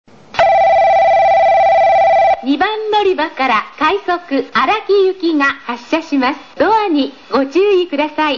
発車放送（快速・荒木）